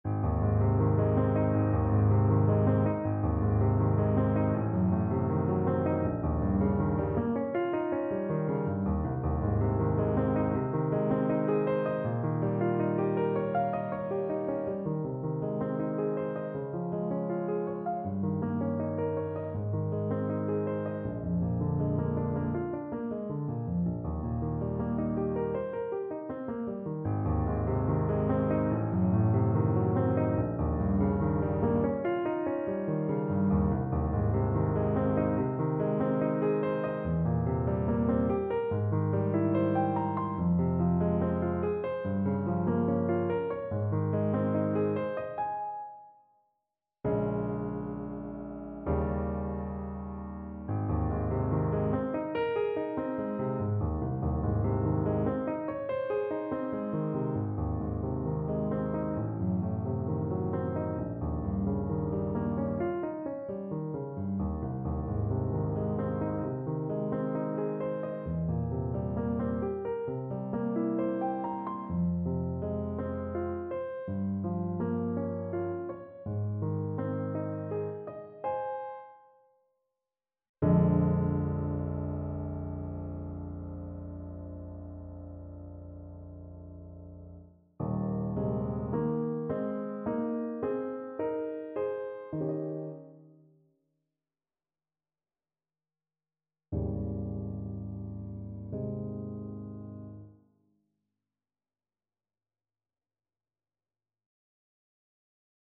Clarinet  (View more Easy Clarinet Music)
Classical (View more Classical Clarinet Music)